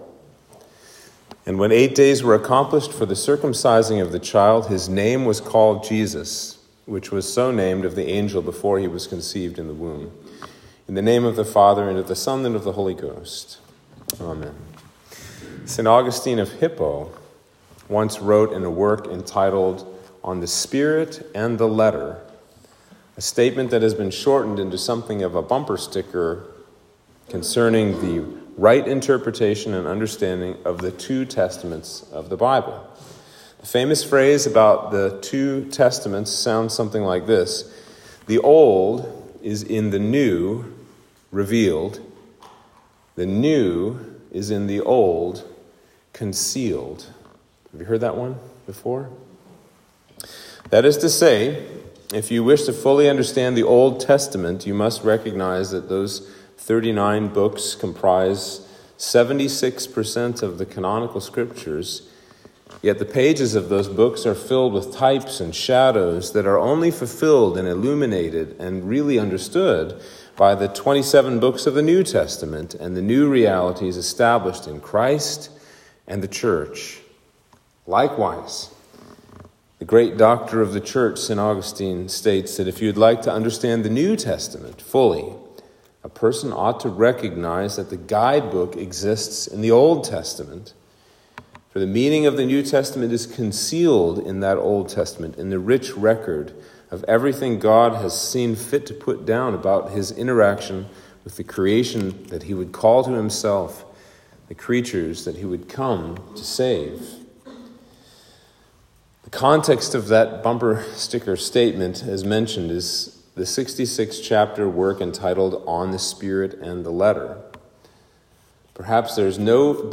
Sermon for The Circumcision of Christ